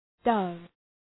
Shkrimi fonetik {dʌv}